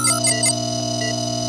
running.wav